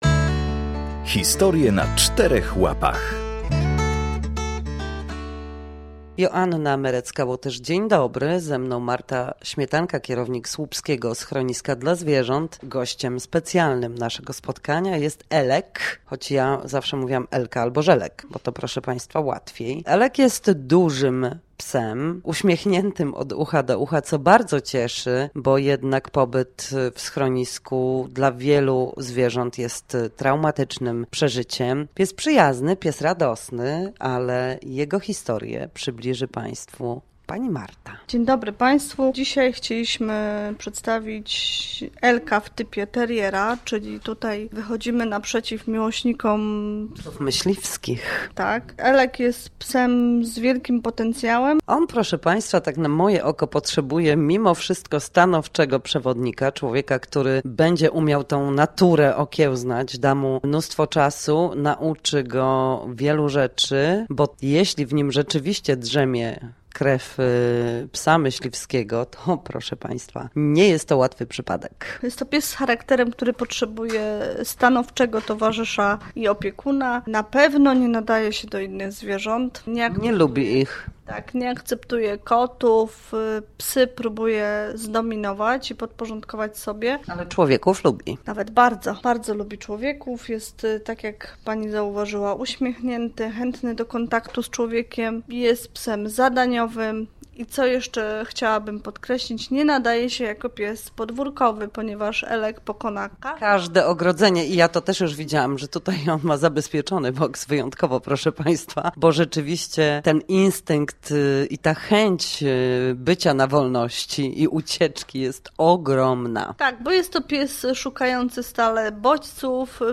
W drugim odcinku, prosto ze słupskiego schroniska dla zwierząt, historia Elka, dużego psa w typie teriera.